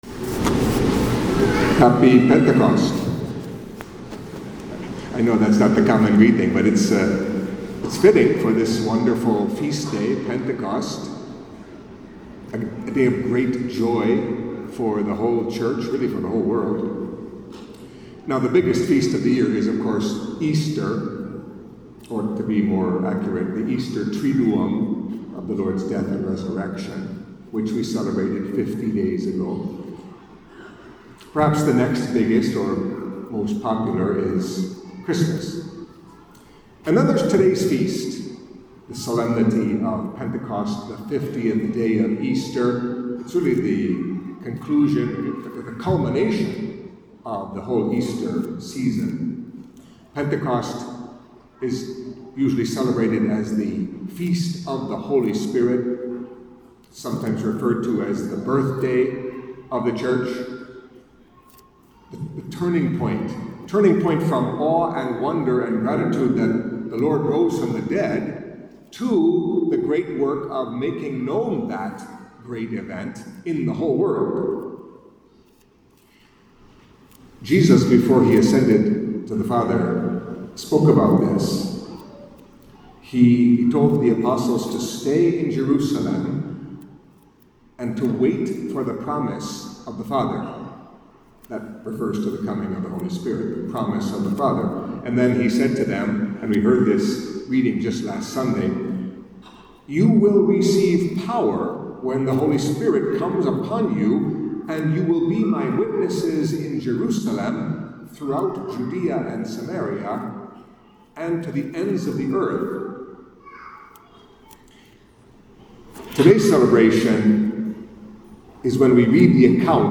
Catholic Mass homily for Pentecost Sunday